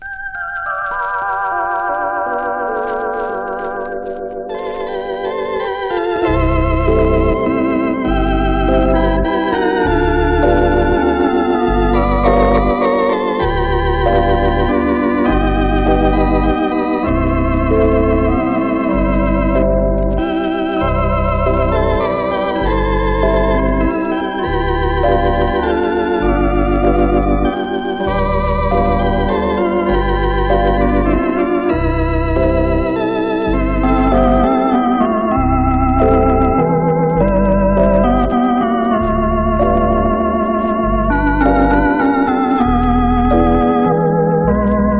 小編成でゆったりとリラックスしつつ、巧みな演奏が嬉しい1枚です。
Jazz, Easy Listening　USA　12inchレコード　33rpm　Mono